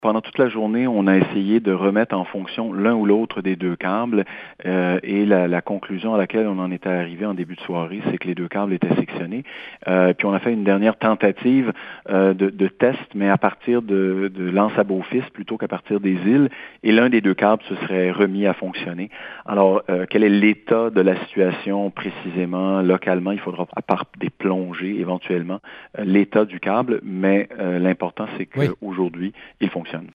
Le député des Îles, Joël Arseneaul explique comment le service de communication est revenu.